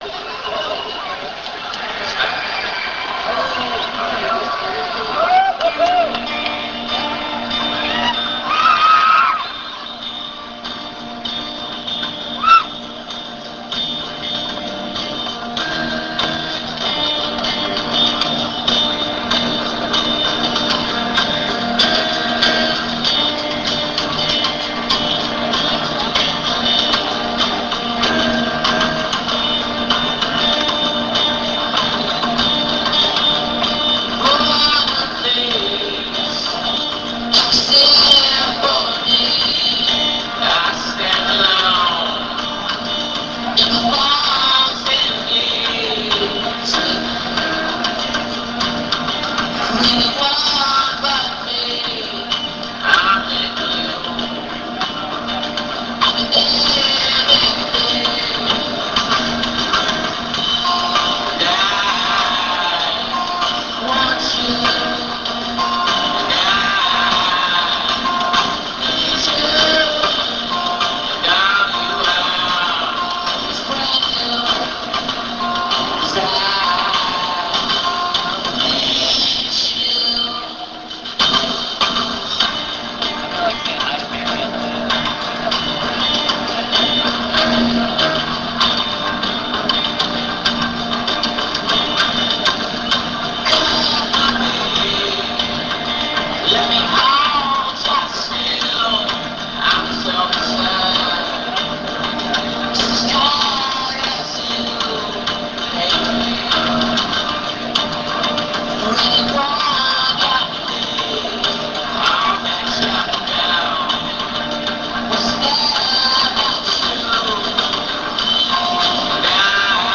Bridge School Benefit; San Francisco, USA
acoustique. on line